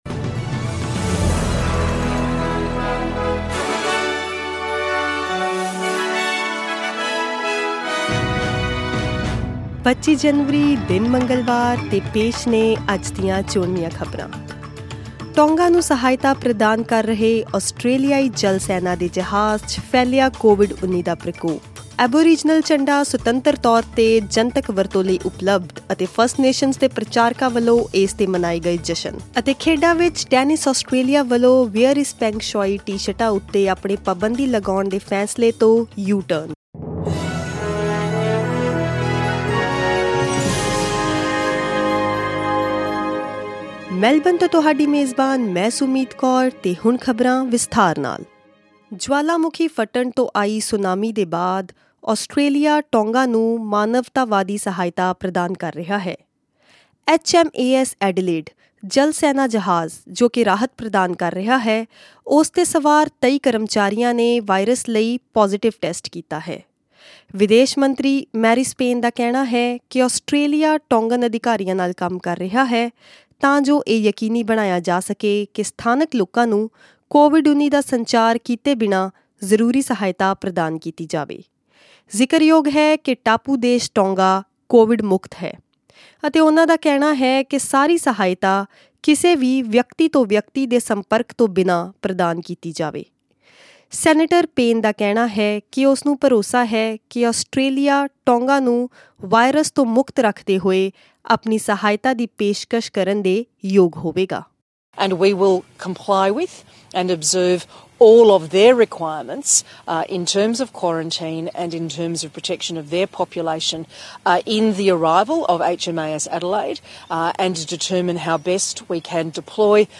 Listen to the latest news headlines in Australia from SBS Punjabi radio.
news_punjabi_25_jan.mp3